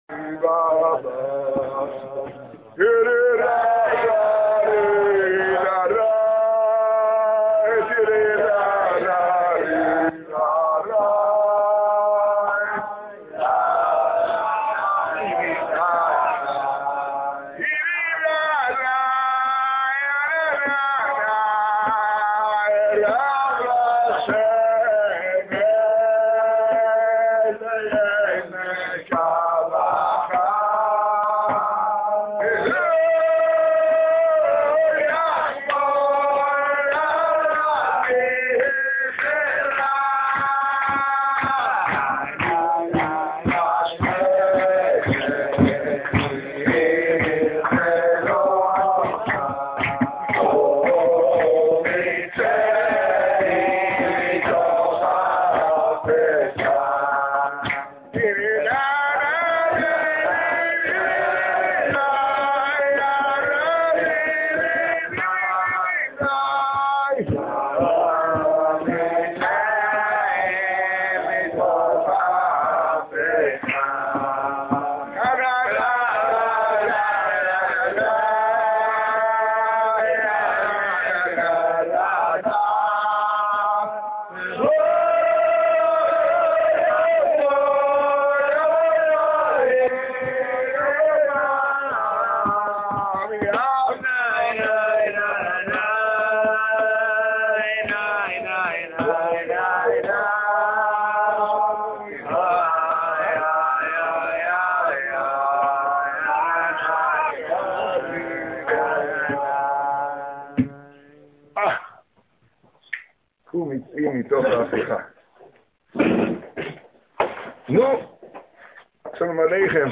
השיעור באולגה, ערב י' טבת תשעה.